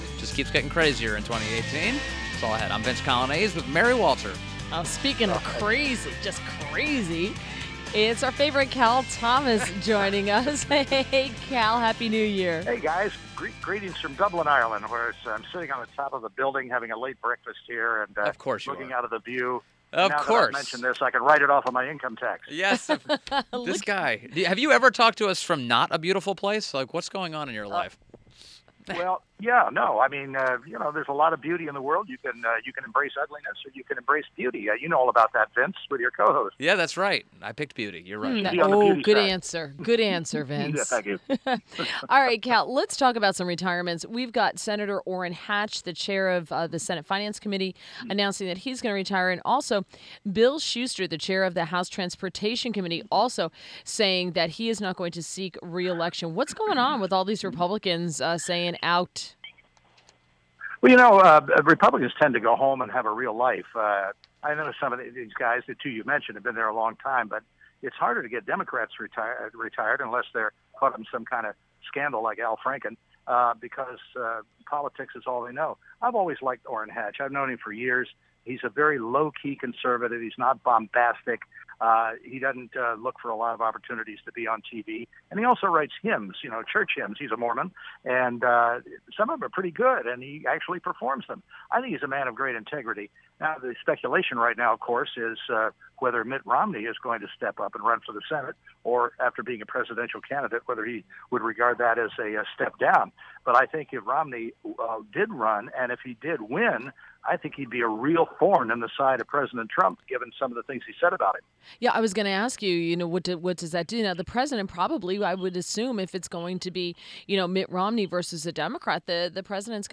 INTERVIEW – CAL THOMAS – Syndicated Columnist – joined WMAL from Dublin, Ireland and discussed Sen. Hatch retiring and gave his 2018 political preview.